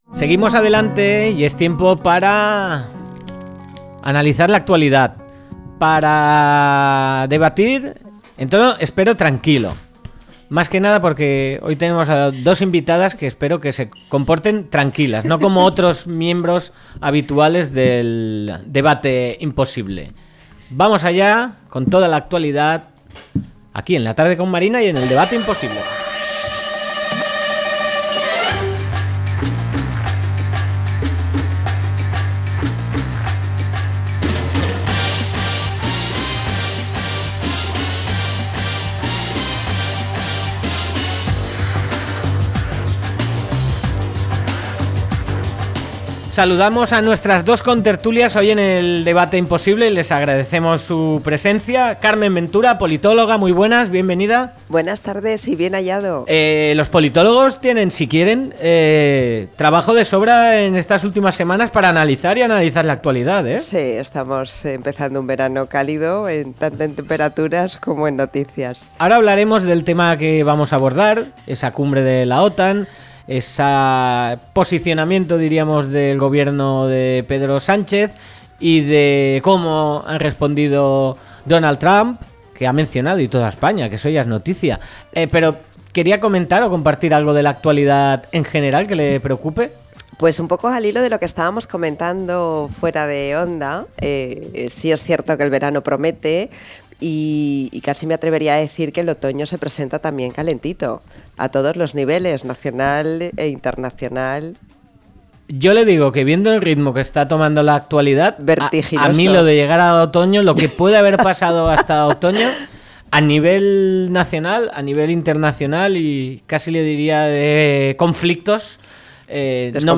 0626-LTCM-DEBATE.mp3